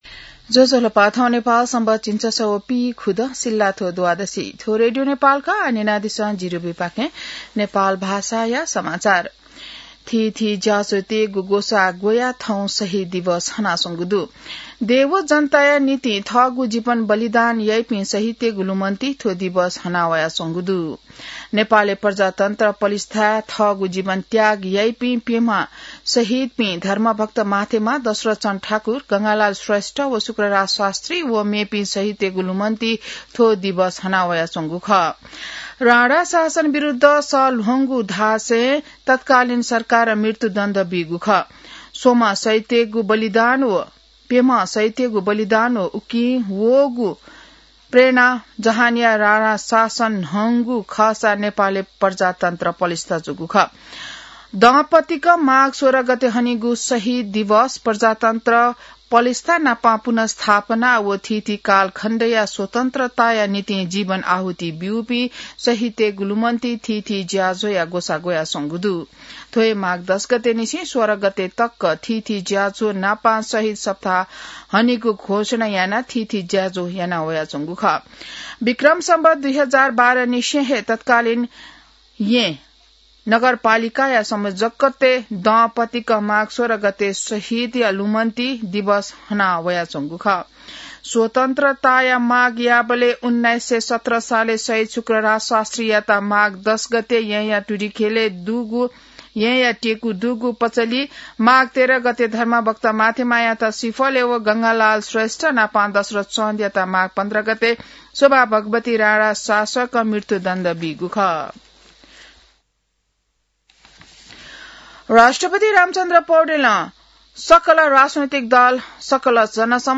नेपाल भाषामा समाचार : २८ माघ , २०८२